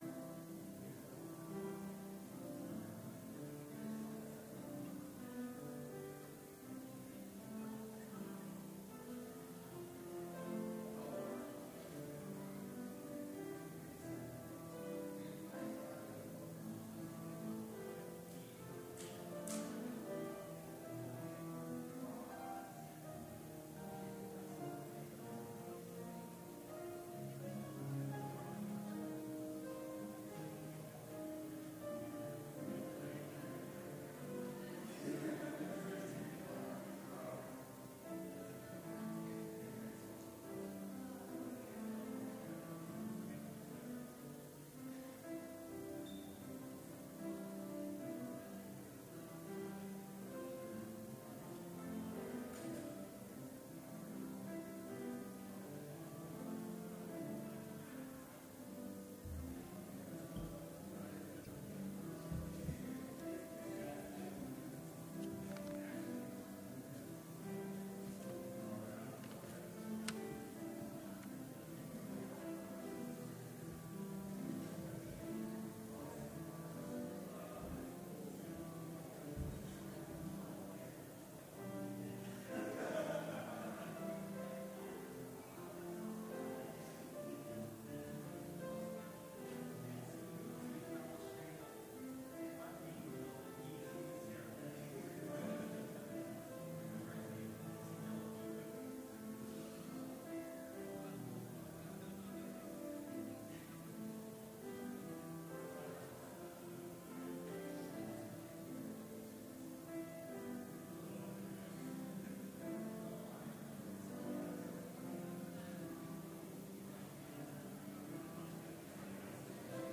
Complete service audio for Chapel - March 20, 2019